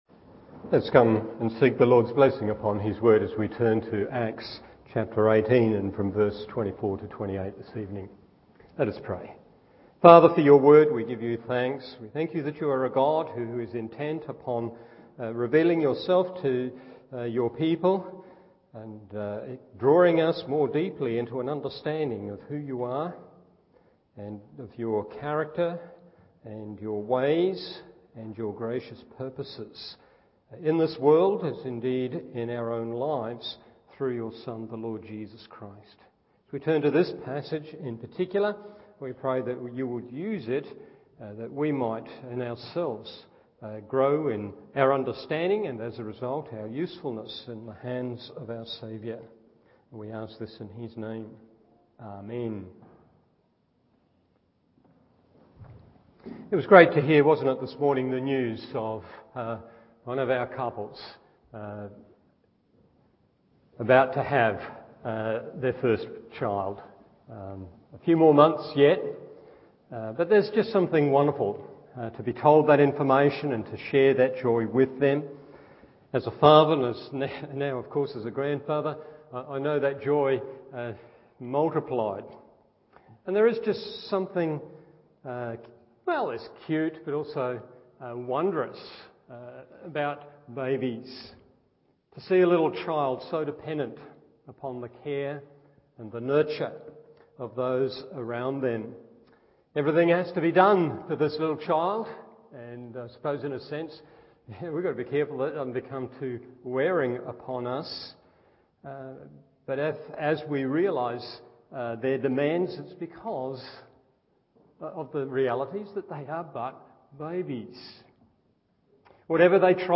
Evening Service Acts 18:24-28…